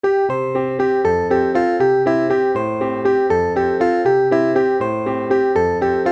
canciones infantiles
Partitura para piano, voz y guitarra.